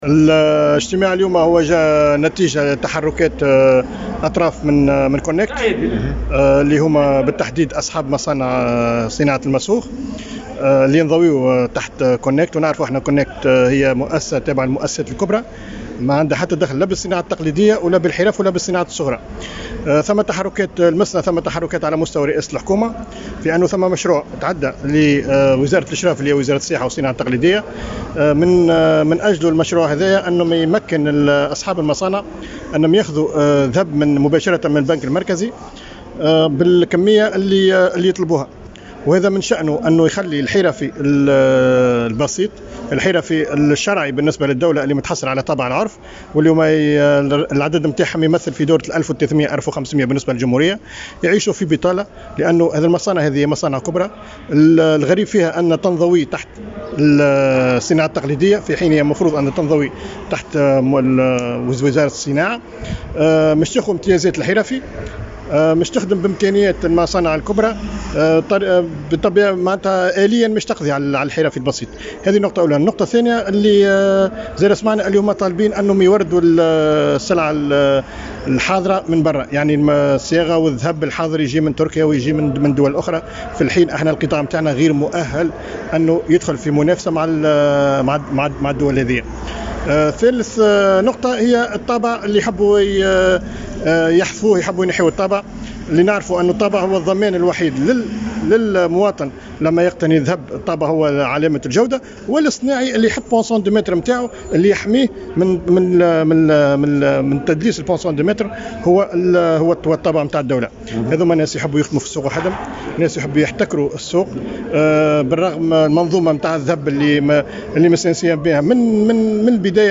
في تصريح لمراسل الجوهرة أف أم